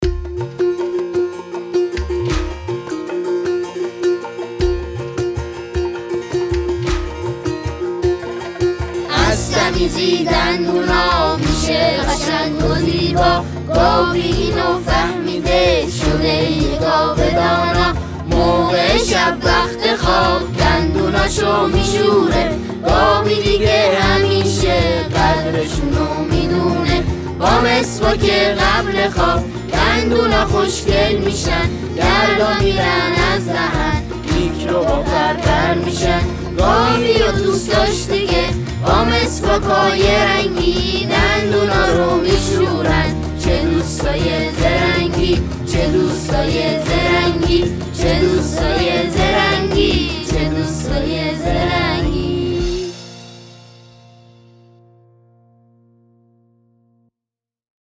🎵 آهنگ کودکانه 🎉
آهنگ این داستان